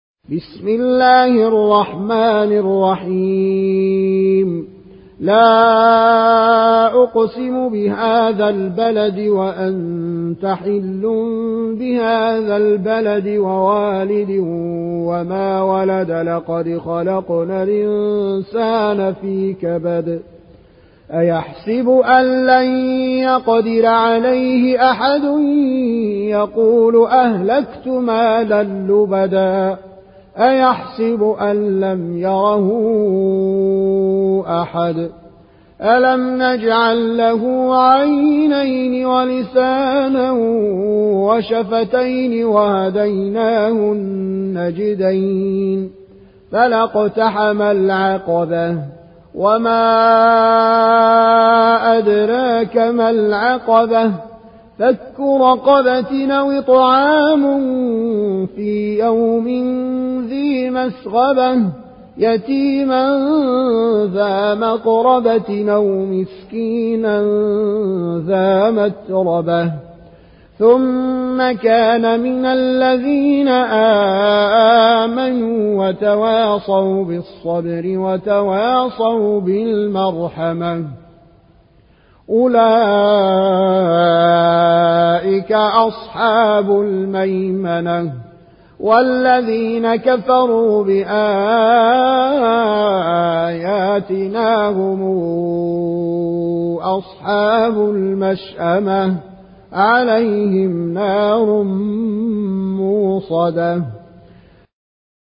Riwayat Warsh from Nafi